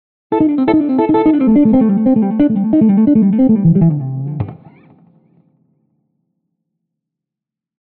HYBRID PICKING STUDIAMO QUALCHE LICK